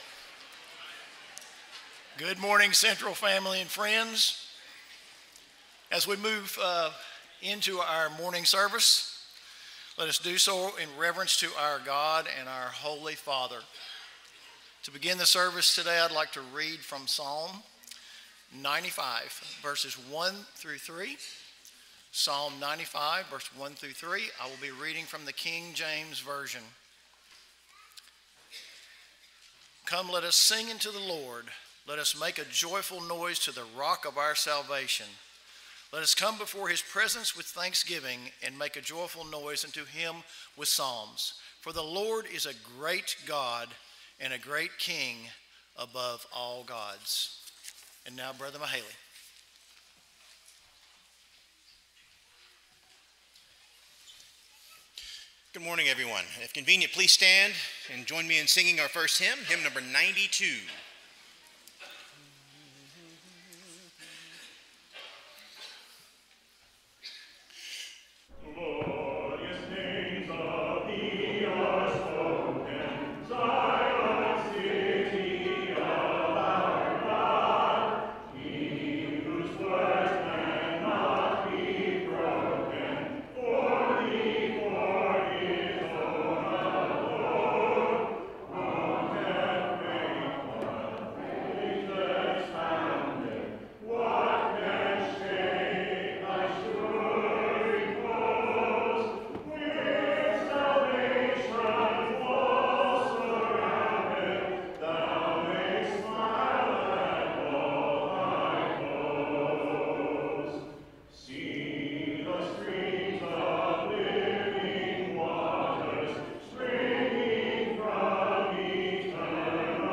James 4:7, English Standard Version Series: Sunday AM Service